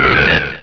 Cri de Tarinor dans Pokémon Rubis et Saphir.